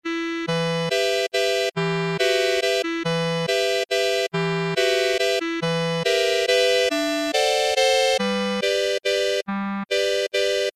Sampling audio d'un accordéon
Le spectre n'est absolument pas contraint à la 13ème harmonique mais le son est absolument horrible. Il est métallique à souhait, strident, monophonique, sans aucune profondeur : 🎧